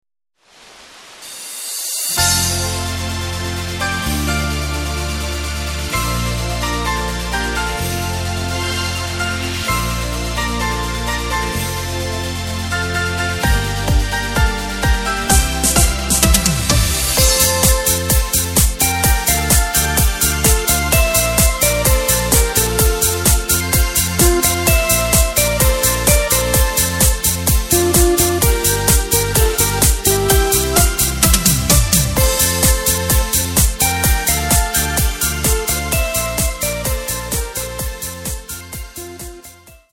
Takt:          4/4
Tempo:         120.00
Tonart:            F
Discofox aus dem Jahr 2022!